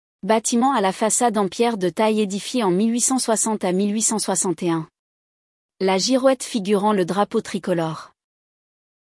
audio guide Mairie